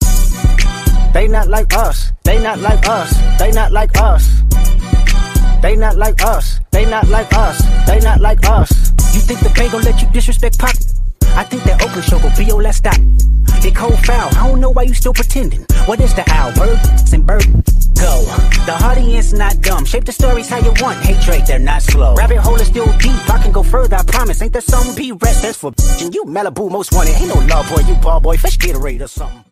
A Shocking Diss Track
If you’re into hip-hop and love diss tracks